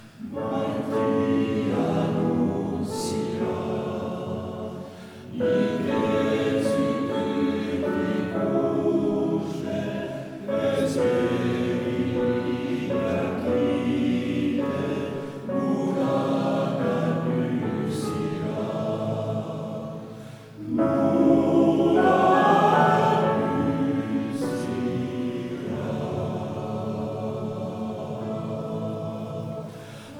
Chant traditionnel